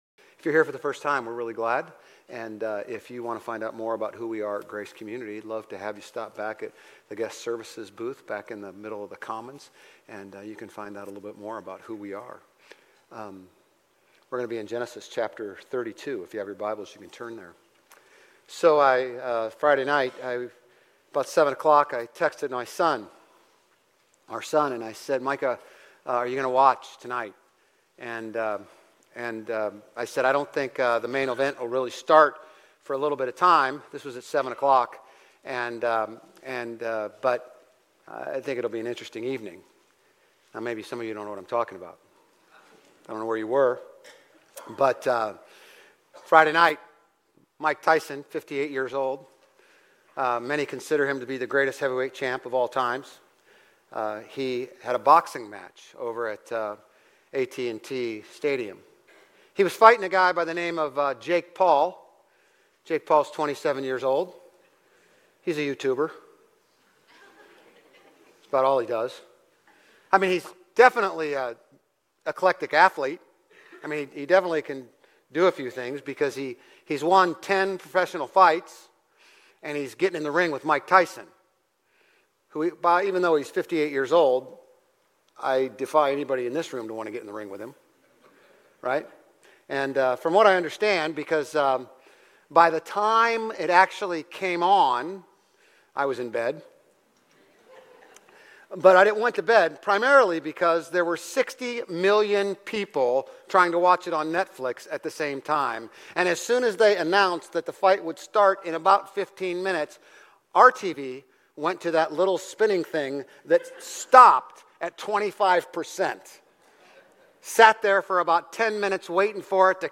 Grace Community Church Old Jacksonville Campus Sermons Gen 32:22-32 - Jacob wrestling with God Nov 17 2024 | 00:33:42 1x 00:00 / 00:33:42 Subscribe Share RSS Feed Share Link Embed